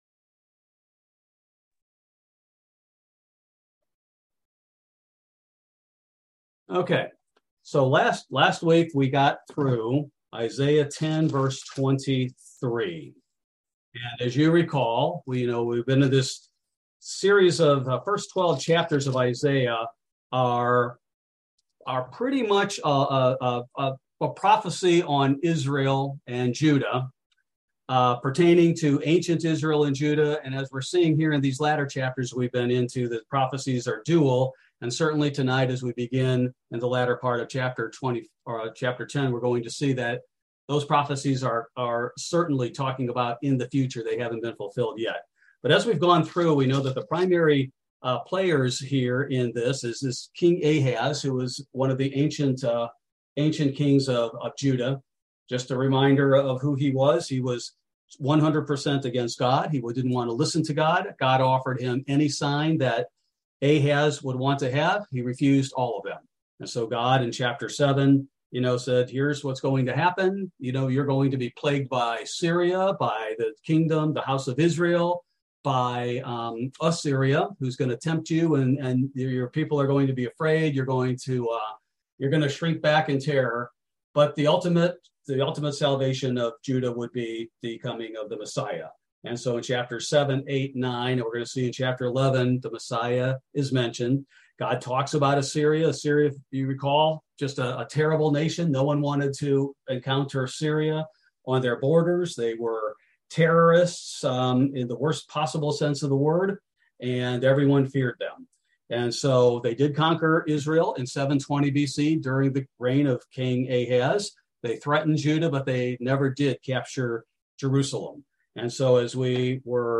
Bible Study: September 7, 2022